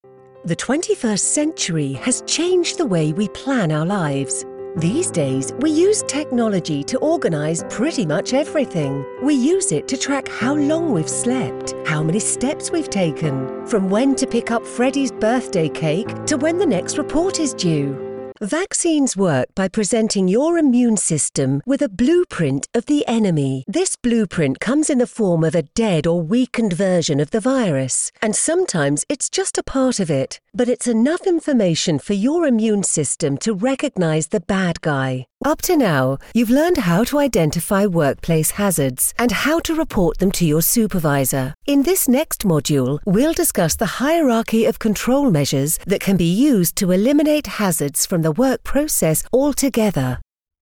Velvety, smooth and sophisticated UK voice actor with a multitude of character voices!
Corporate Demo